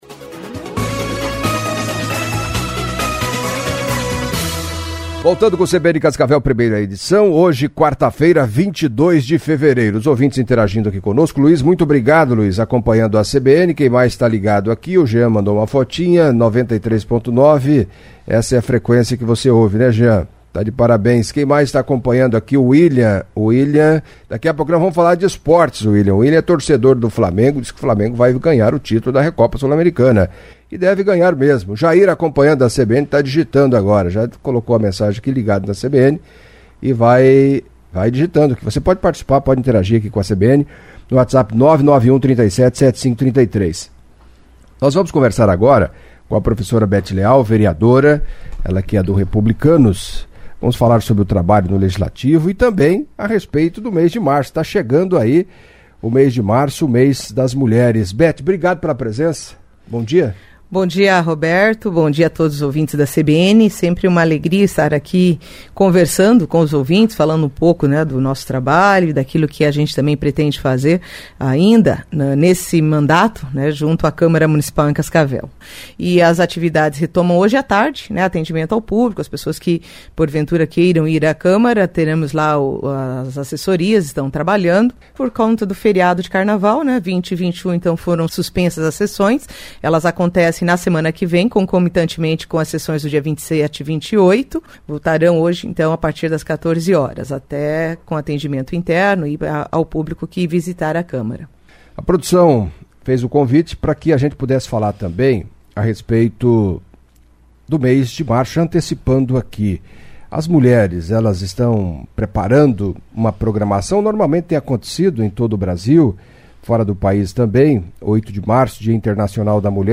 Em entrevista à CBN nesta quarta-feira (22) a professora Beth Leal, doutora em Direito e vereadora em Cascavel, falou dos direitos da mulher que têm avançado, mas, segundo ela, muita coisa ainda precisa acontecer e "a caminhada é longa". Beth Leal falou das desigualdades entre homens e mulheres no trabalho, entre outros assuntos, lamentou os abusos que ocorrem, diariamente, nos mais diversos lugares de assédio e importunação sexual, por exemplo.